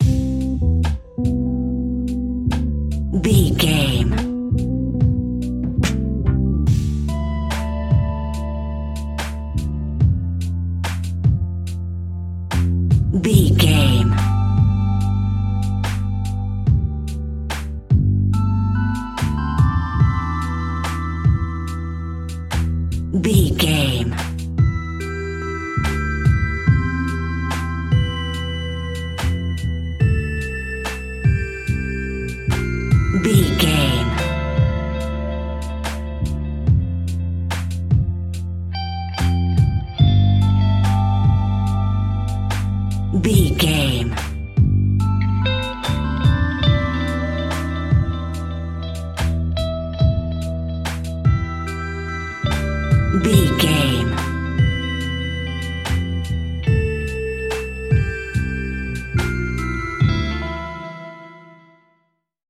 Ionian/Major
E♭
chilled
laid back
Lounge
sparse
new age
chilled electronica
ambient
atmospheric